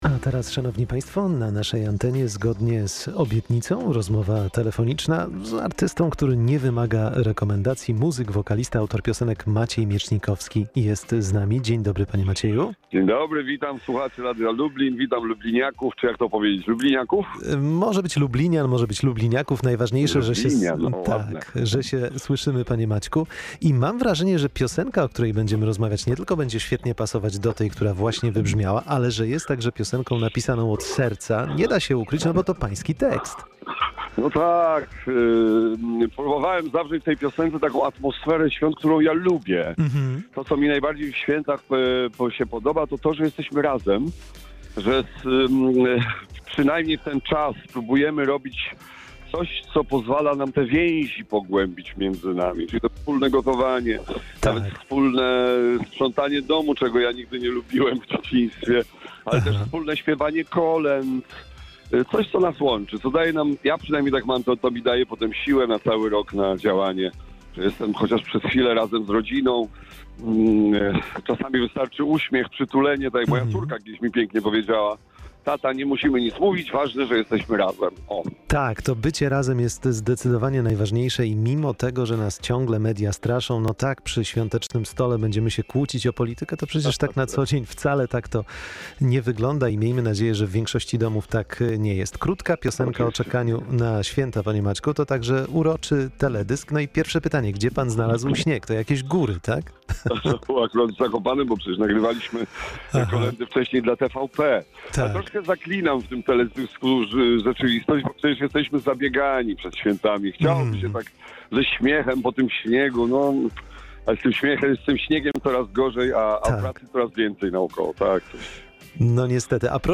Maciej Miecznikowski ma dla nas muzyczny prezent pod choinkę [POSŁUCHAJ ROZMOWY]